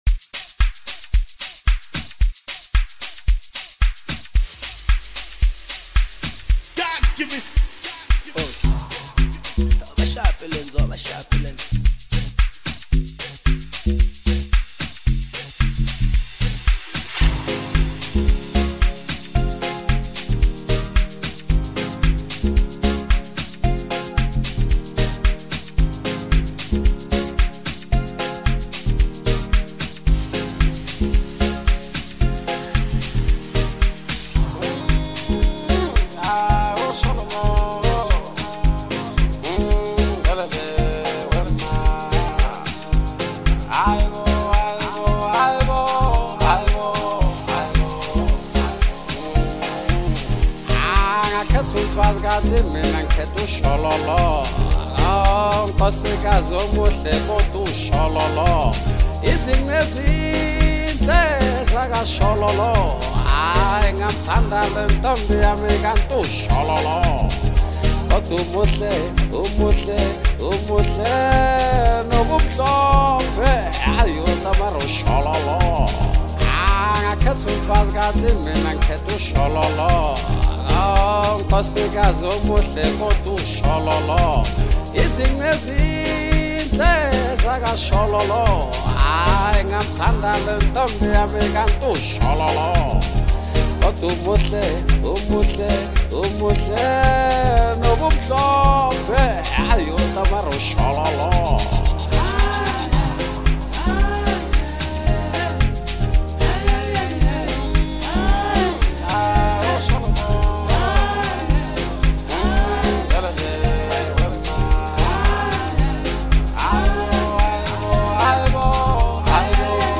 strings
smooth vocals